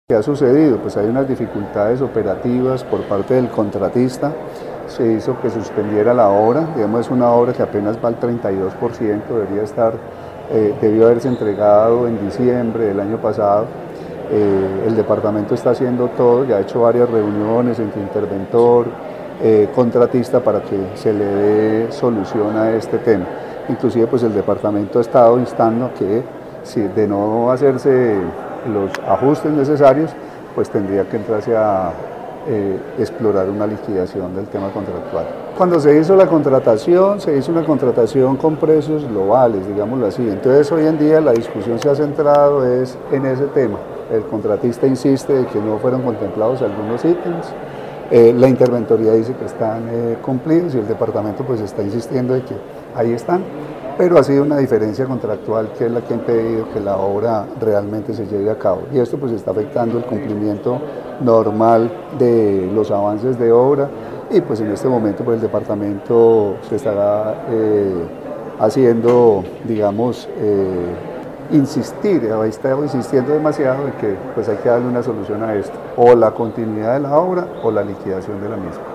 Carlos Alberto Gómez, secretario de salud